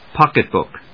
pócket・bòok